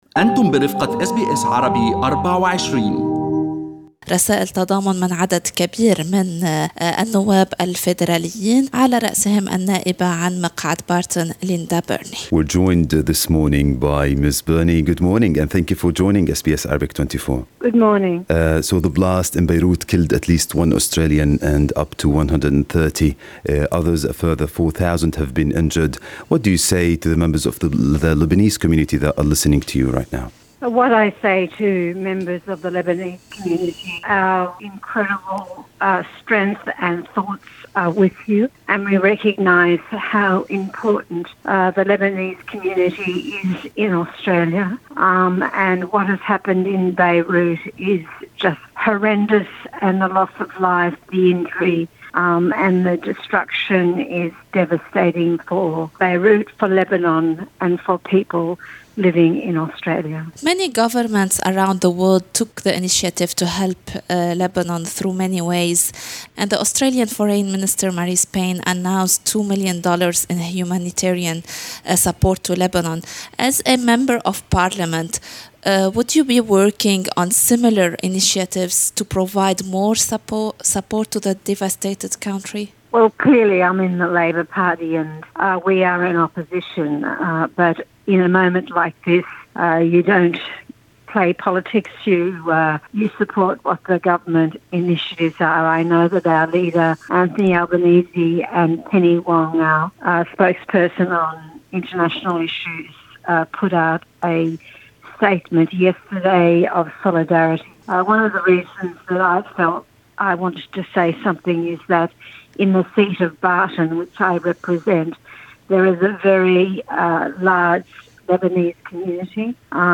استمعوا إلى المقابلة مع النائبة ليندا بيرني في الملف الصوتي المرفق بالصورة (باللغة الانجليزية).